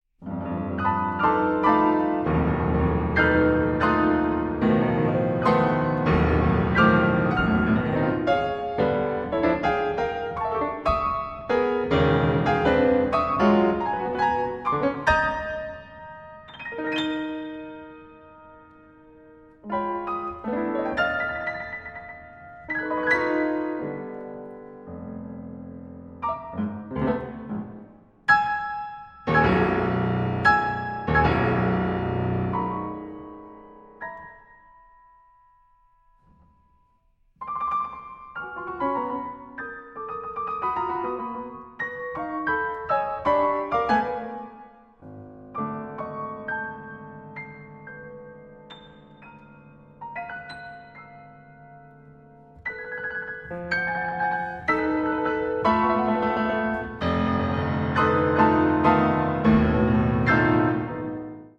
piano
The solo piano work